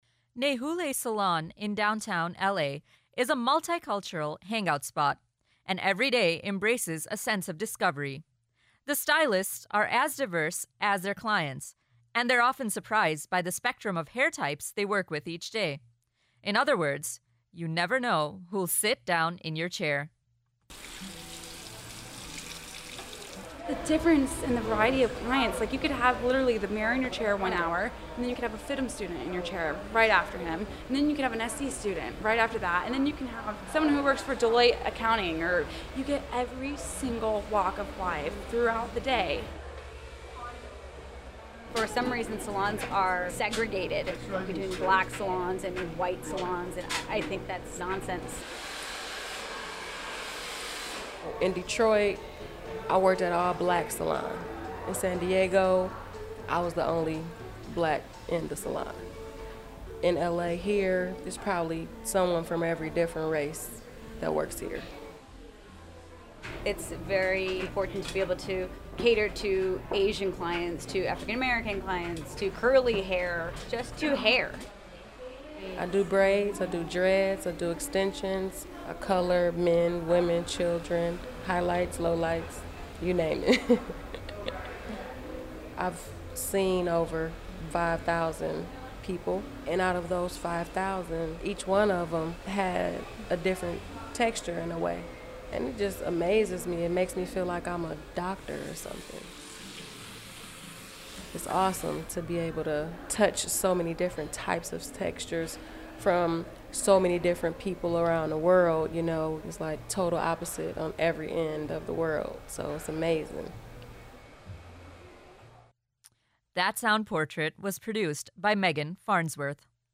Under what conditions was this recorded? Hair Salon WH.mp3